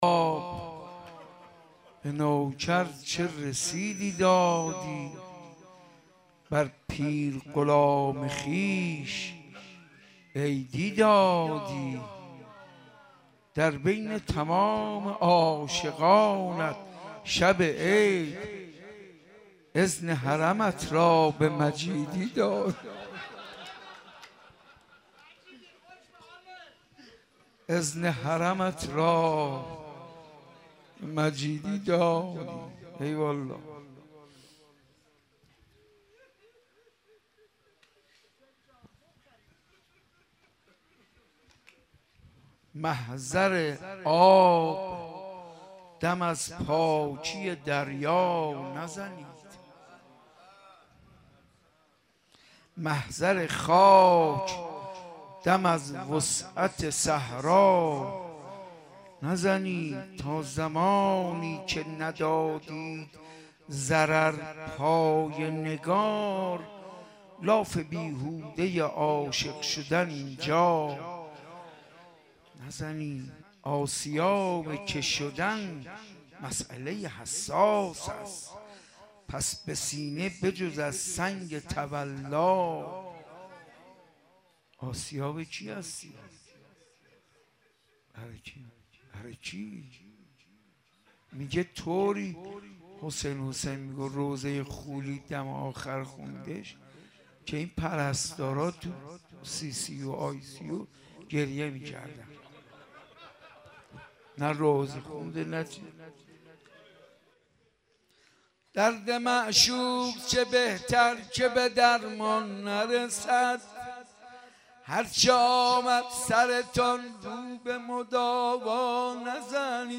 این مراسم با نماز جماعت مغرب و عشاء و تلاوت قرآن کریم آغاز گردید